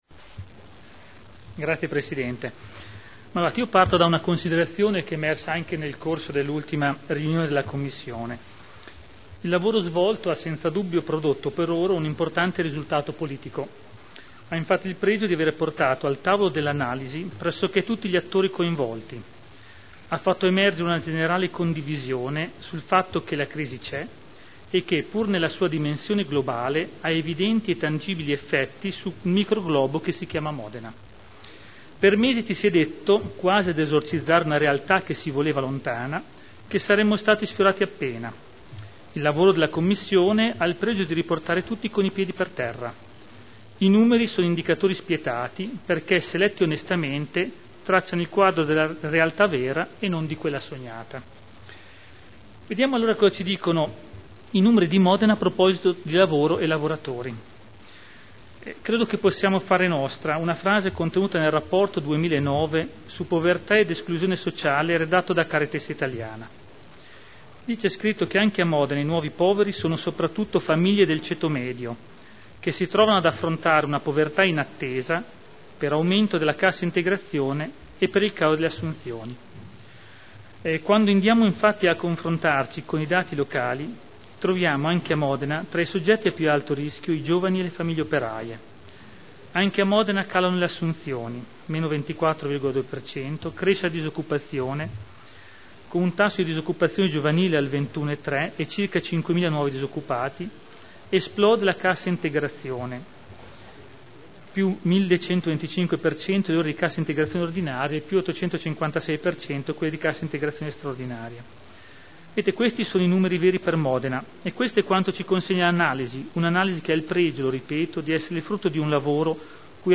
Gian Domenico Glorioso — Sito Audio Consiglio Comunale